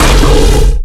giant_attack_2.ogg